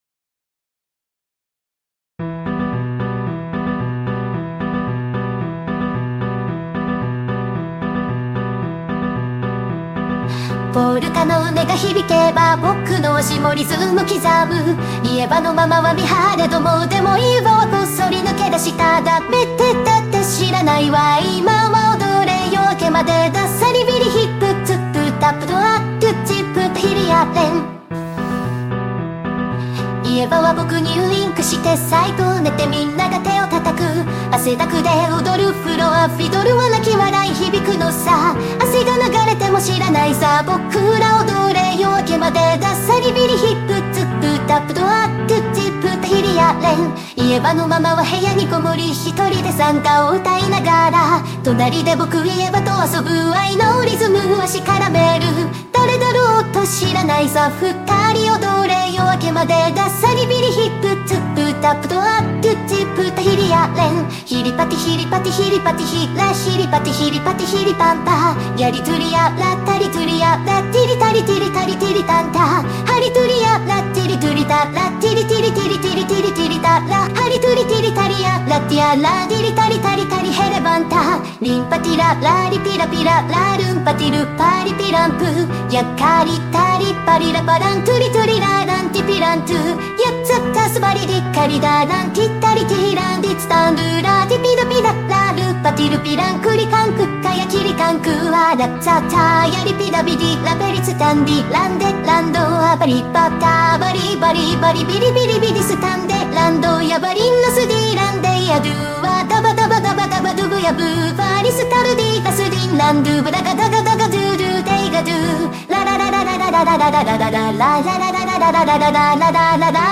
Finnish Folk Song 【Japanese cover】